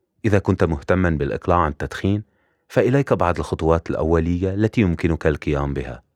All voiceover projects are recorded and mixed by our in-house audio engineers to ensure high fidelity and natural sounding recordings.
Some of our Voice Over Samples
Arabic – Male voice
2023-Arabic-Male.wav